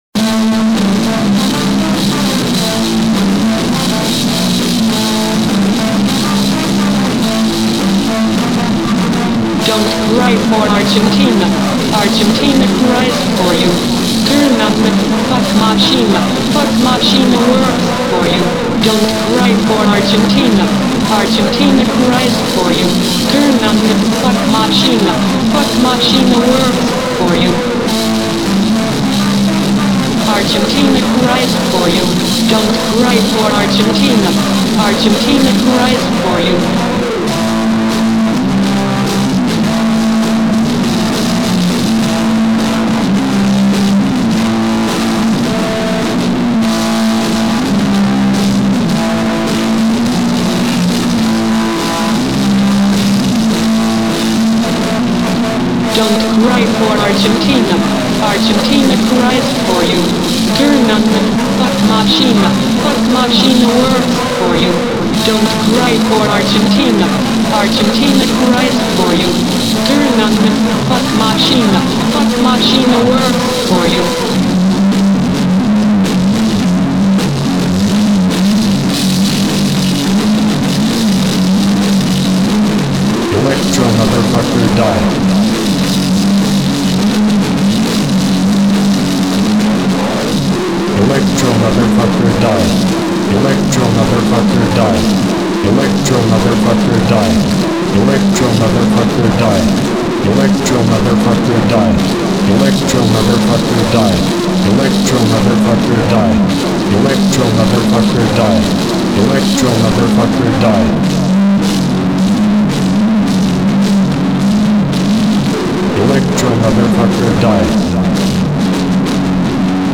A female artist